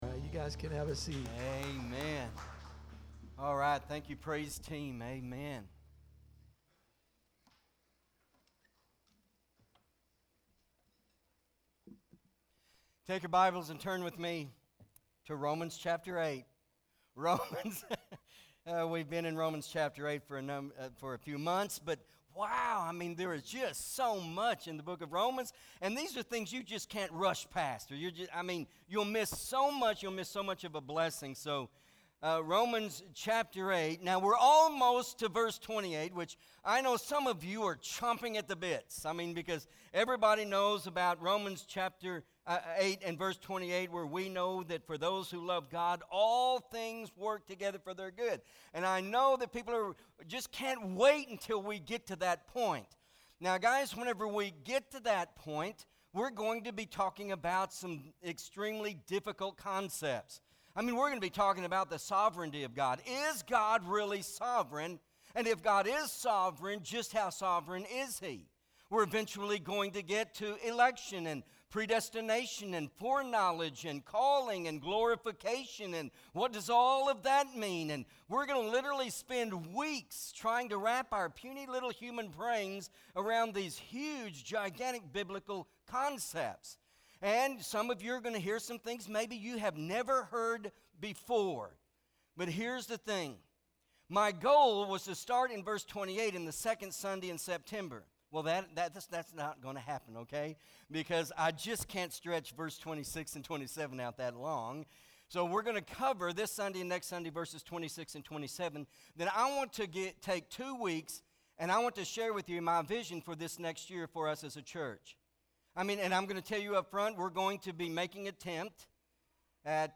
Pt. 1 MP3 SUBSCRIBE on iTunes(Podcast) Notes Sermons in this Series Romans 8: 18-27 Not Ashamed!